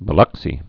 (bə-lŭksē, -lŏk-)